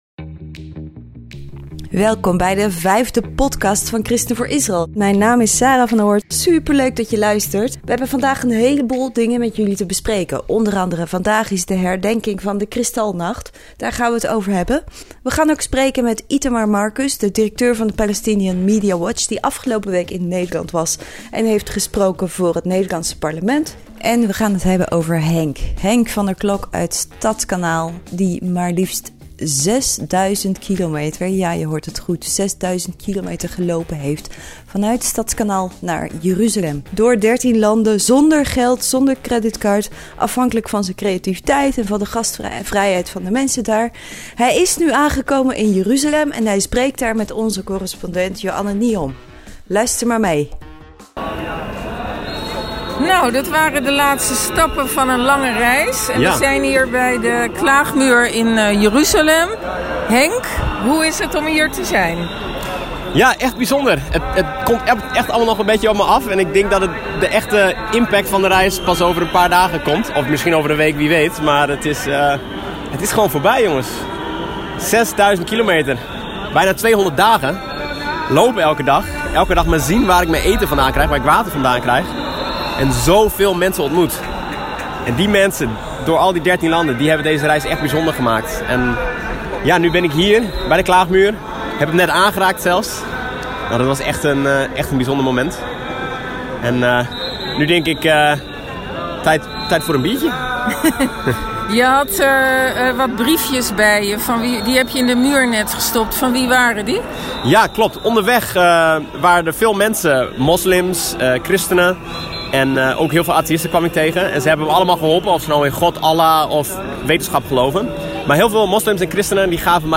En een interview met een man